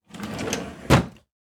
Drawer Open Sound
household